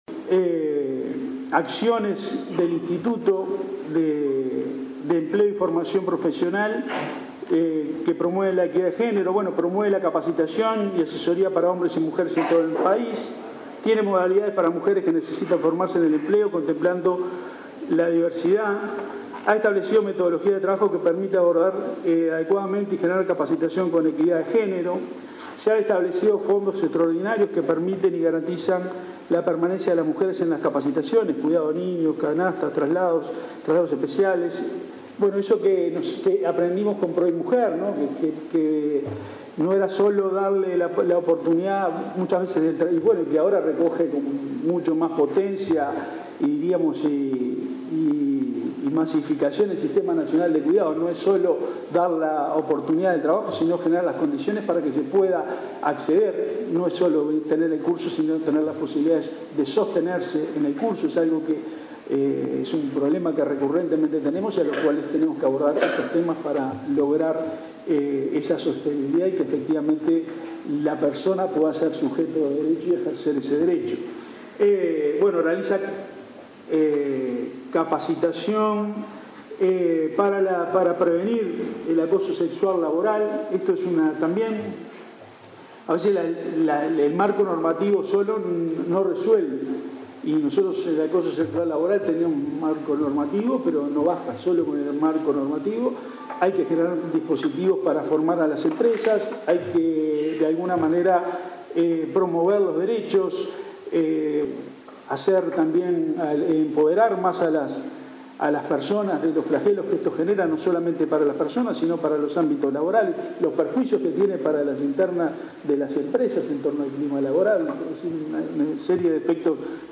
En el marco de los 30 años de la Casa de la Mujer, el director nacional de Empleo, Eduardo Pereyra, habló del trabajo de Inefop para promover la equidad de género. Dijo que dispone de fondos que garantizan la permanencia de la mujer en los cursos (cuidado de niños, canastas, traslados); se apuesta a la formación para prevenir el acoso laboral y para apoyar a emprendedoras.